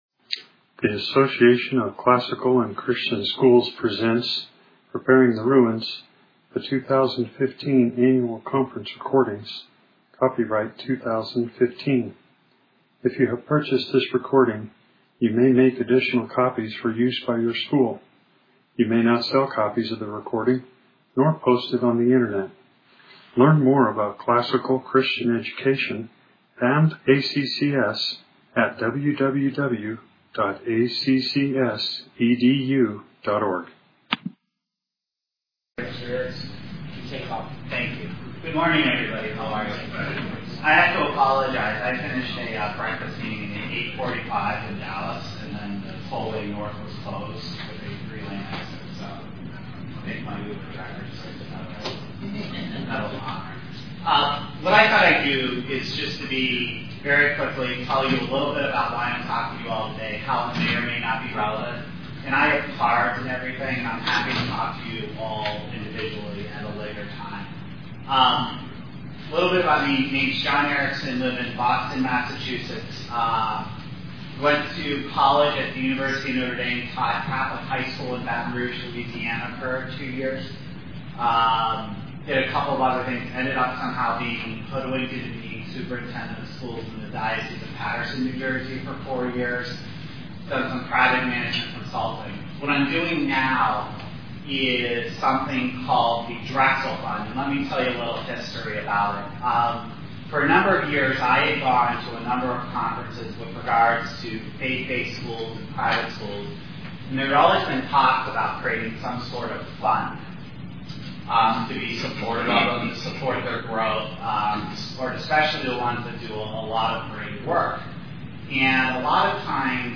2015 Leaders Day Talk | 0:22:25 | All Grade Levels, Leadership & Strategic, Marketing & Growth